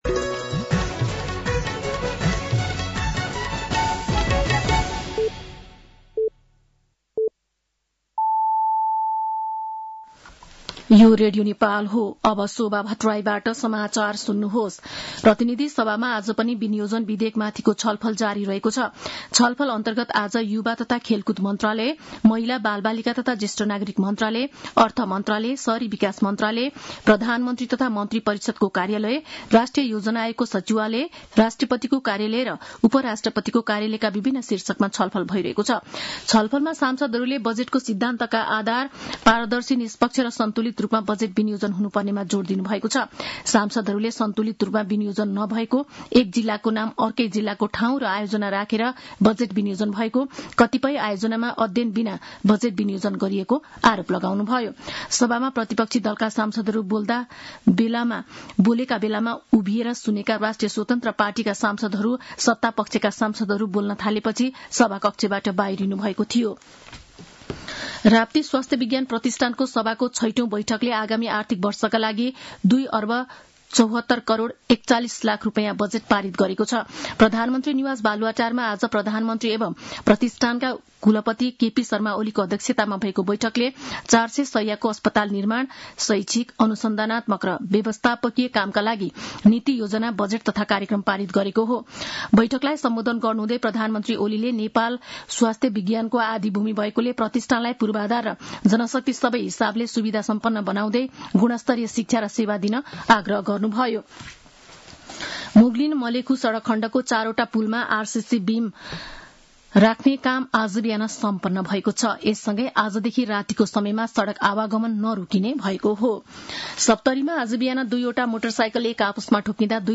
साँझ ५ बजेको नेपाली समाचार : ८ असार , २०८२
5.-pm-nepali-news-1-5.mp3